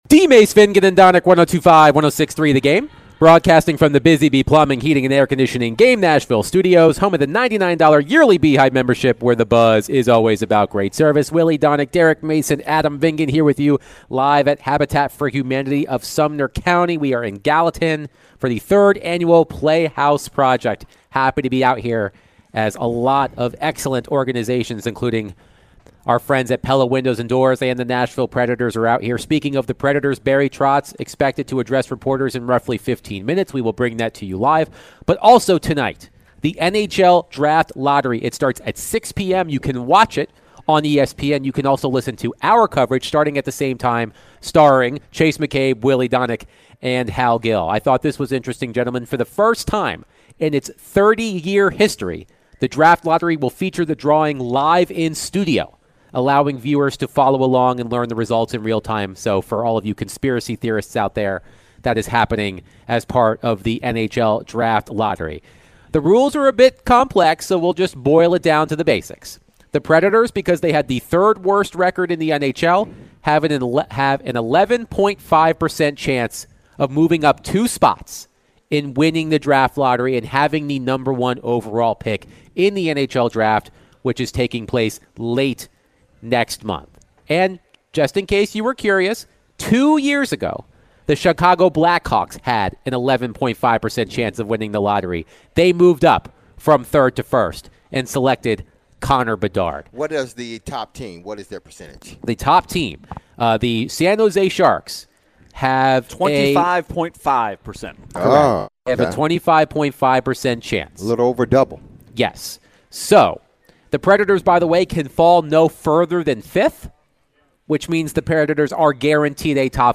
In the second hour, DVD discuss the NHL Draft Lottery and can the Preds get the number one overall pick. They played the Barry Trotz end of the year presser live